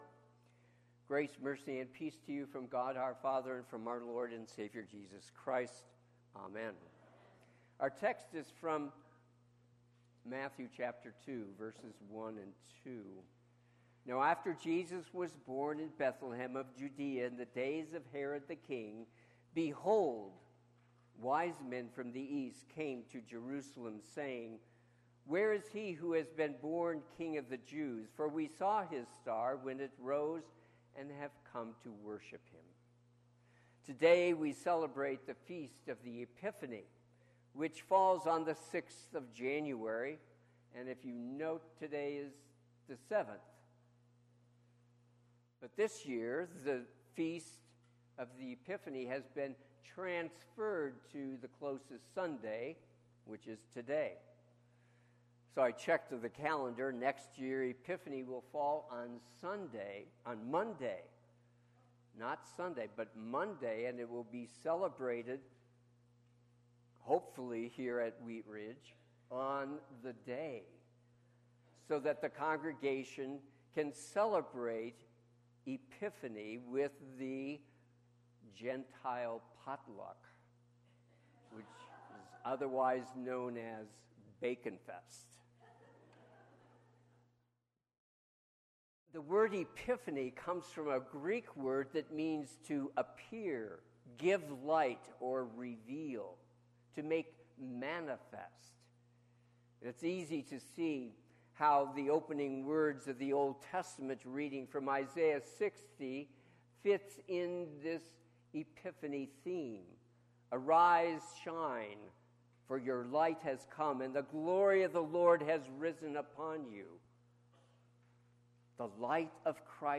Sermon - 01/07/2024 - Wheat Ridge Evangelical Lutheran Church, Wheat Ridge, Colorado